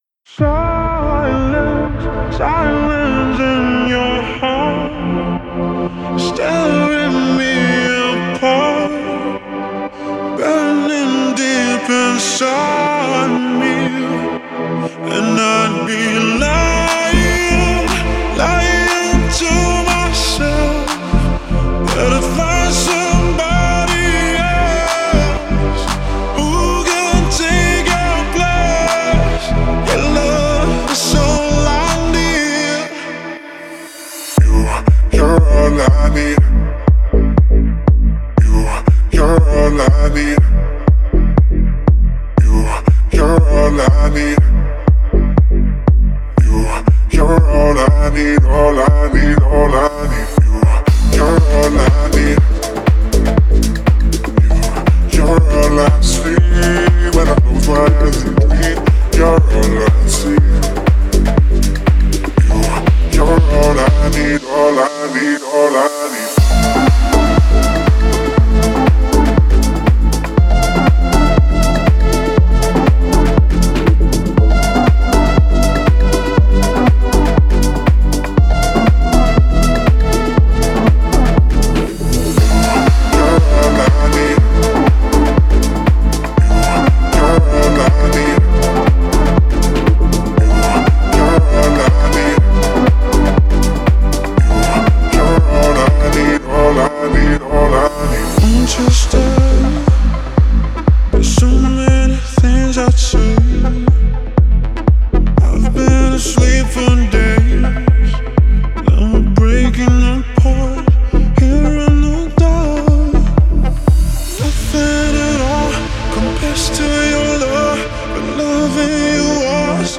яркая поп-композиция